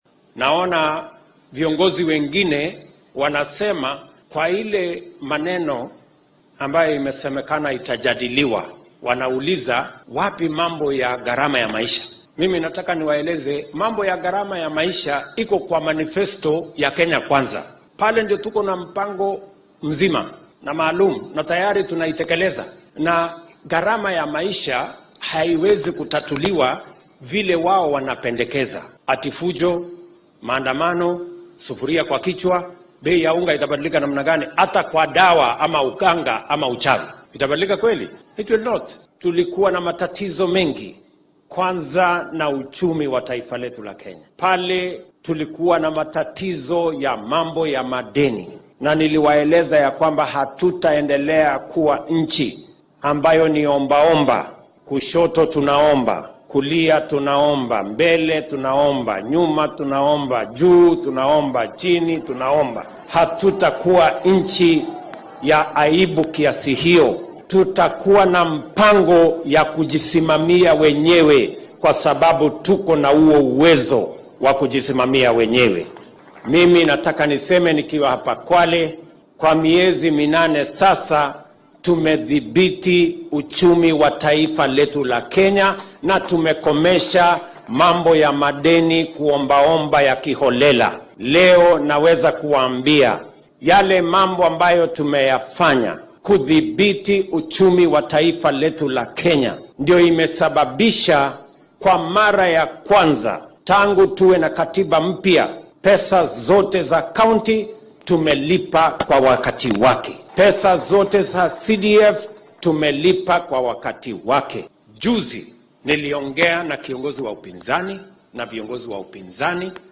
Arrimahan ayuu ka hadlay xilli uu ku sugnaa magaalada Ukunda ee ismaamulka Kwale.